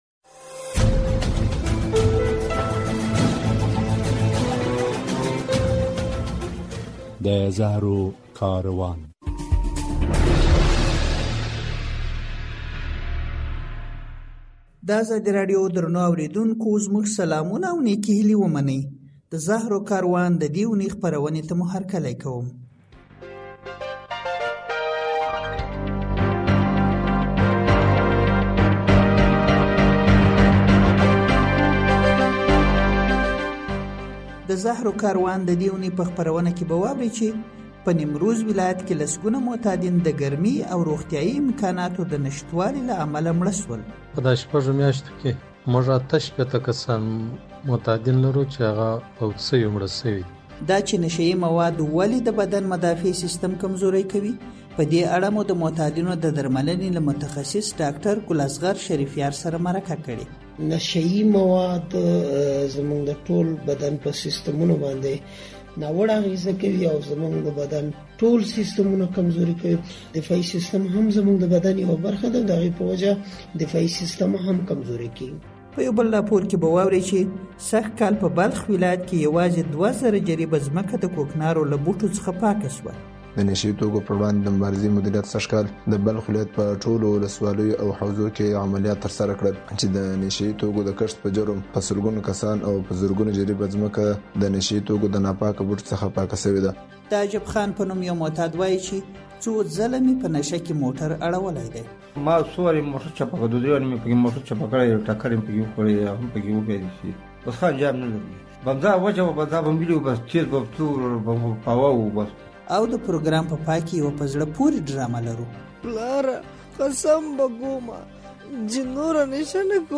د زهرو کاروان د دې اوونۍ خپرونه کې اورئ چې په نیمروز ولایت کې لسګونه معتادین د ګرمۍ او روغتیایي خدمتونو ته د نه لاسرسۍ له امله مړه شوي دي. له یوه متخصص ډاکتر سره په مرکه کې له هغه پوښتل شوي چې معتاد ولې د روغ انسان په پرتله د ګرمۍ او سړې هوا په وړاندې کمزوری وي؟